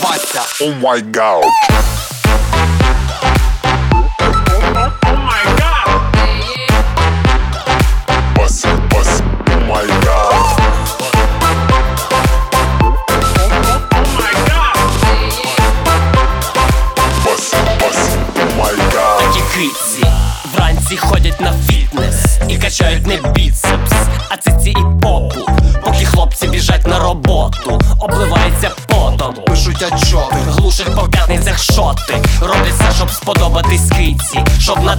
Жанр: Танцевальные / Украинские